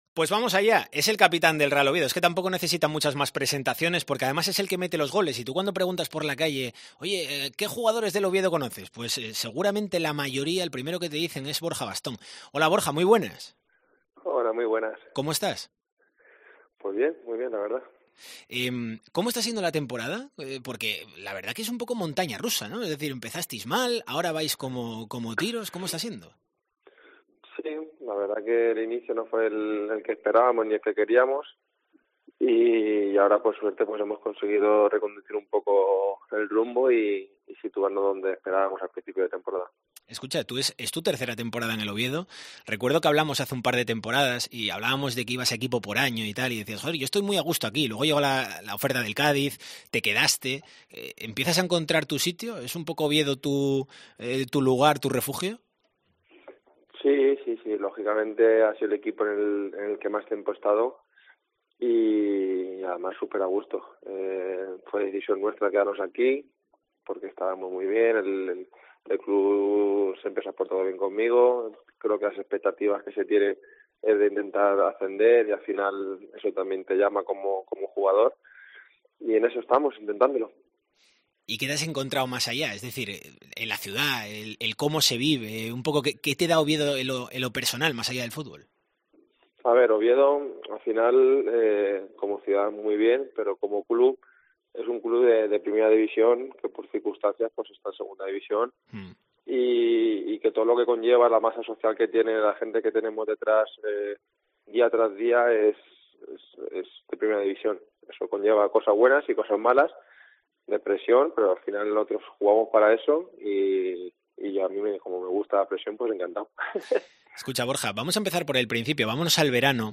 El capitán del Real Oviedo ha concedido una entrevista exclusiva a Deportes COPE Asturias.